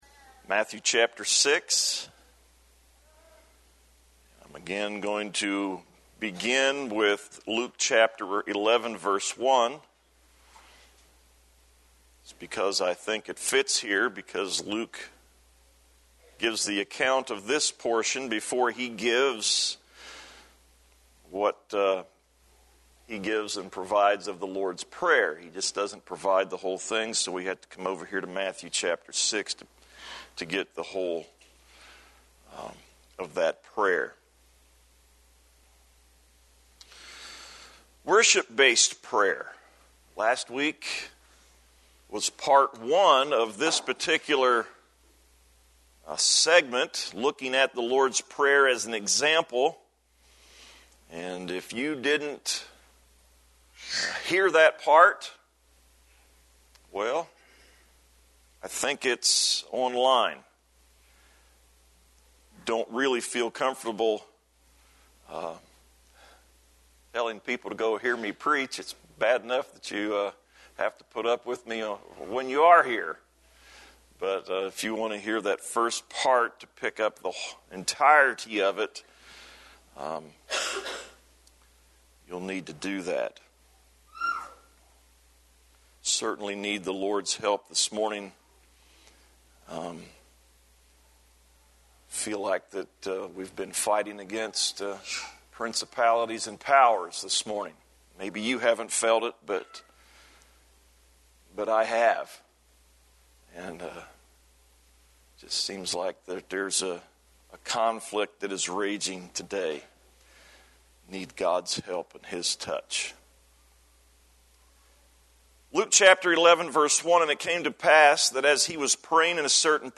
A sermon entitled “Worship-Based Praying